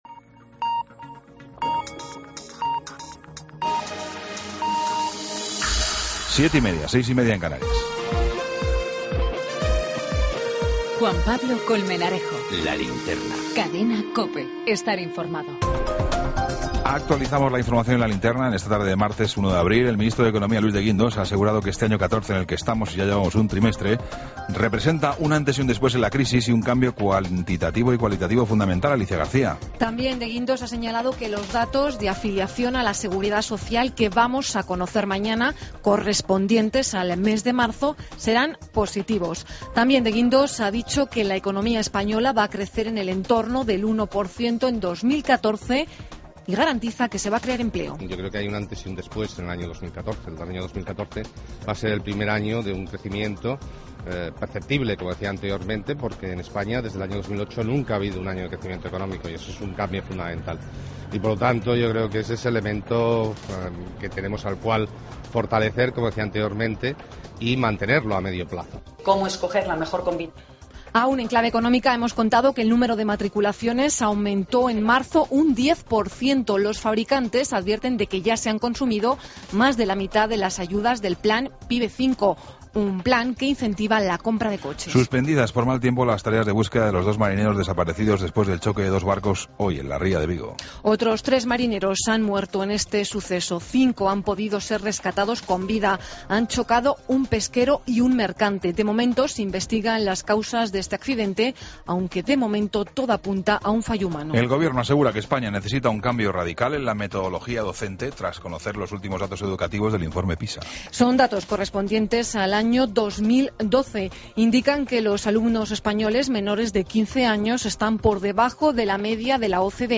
Ronda de corresponsales.
Entrevista a Rufino de la Rosa, Director de Gestión Tributaria de Hacienda.